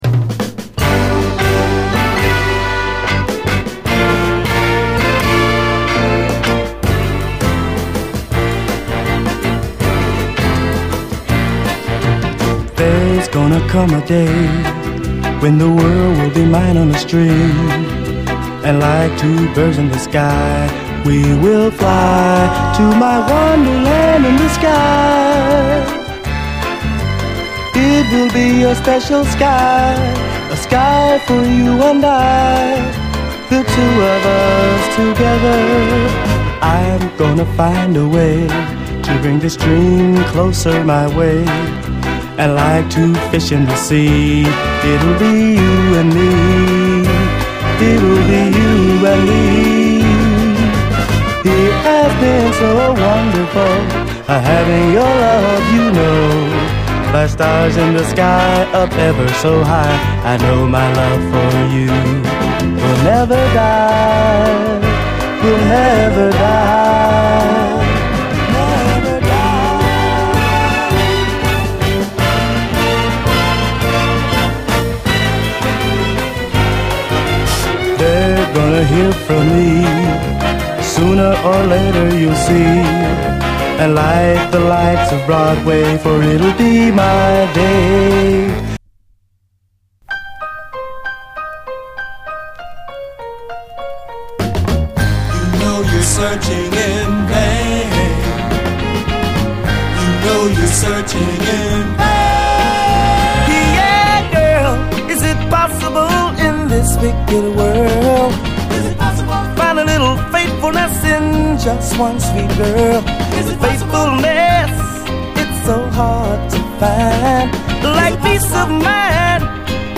60's SOUL, SOUL, 70's～ SOUL